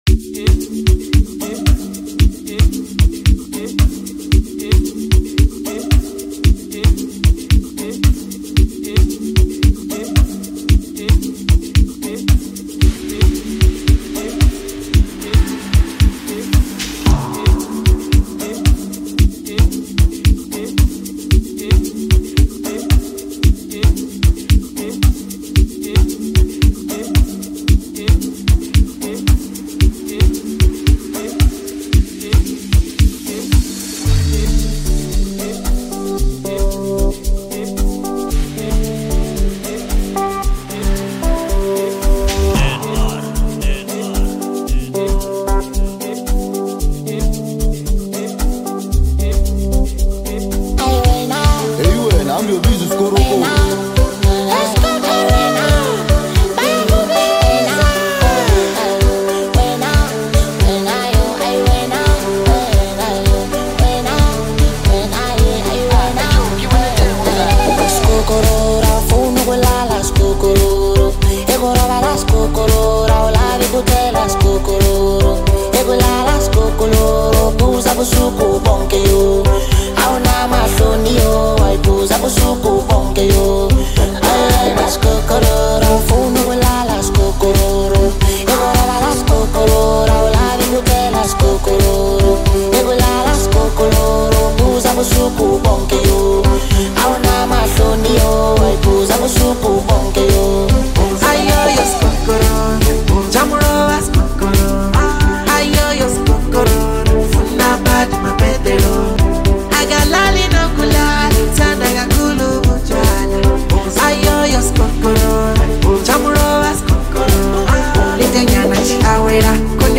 is an energetic and exhilarating track